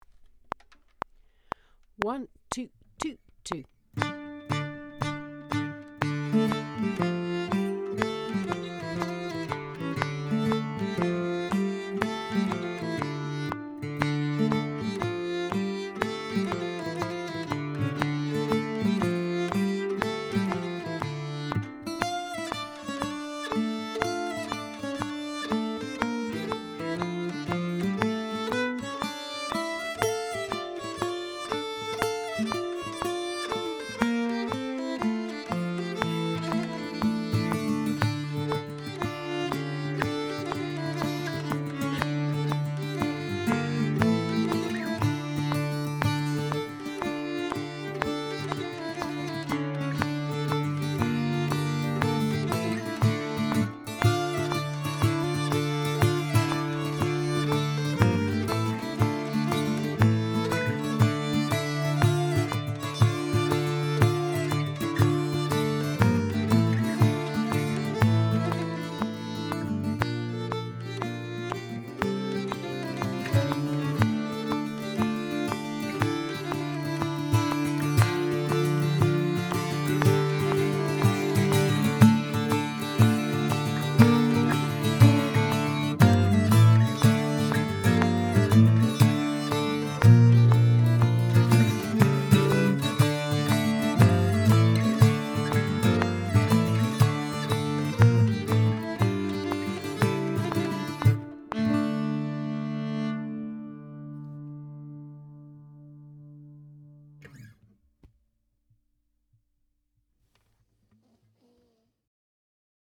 I chose it because it is a 32 bar jig and as such I'd like to blur the lines a little.
(Headphones is crucial) We are playing to a click at 120 bpm - there are two guides below one with a click and one without. We play it three times.
femalesaylorclick.mp3